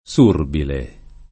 surbile [